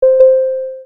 notifikasi.wav